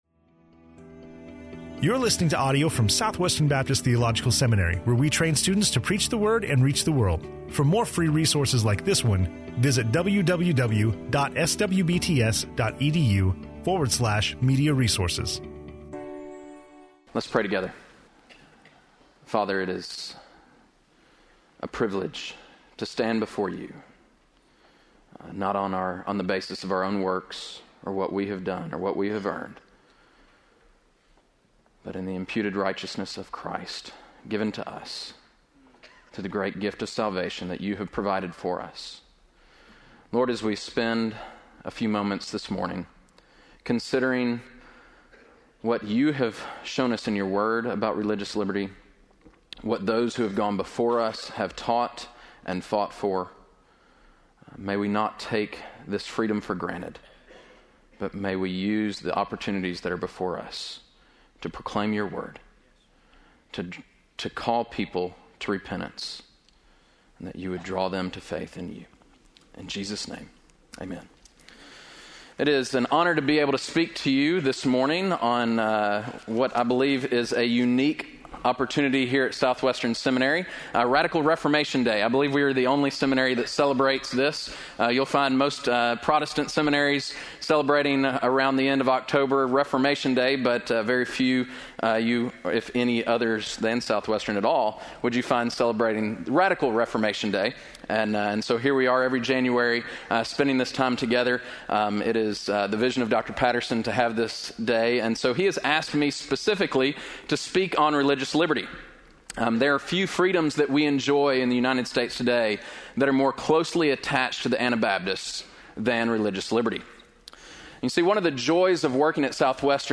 in SWBTS Chapel on Thursday January 30, 2014
SWBTS Chapel Sermons